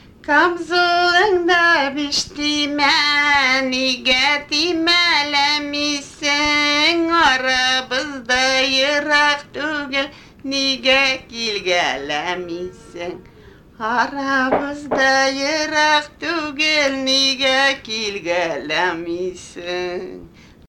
Песенная традиция томских чатов
Песенным мелодиям присуща яркая индивидуальность в сочетании с лирической утонченностью, ювелирная проработка фраз, непринужденность развертывания мелодического рисунка, развитая орнаментика и одновременно удивительная естественность.
Большая часть песен основана на пятиступенных бесполутоновых ладах в объеме сексты–септимы.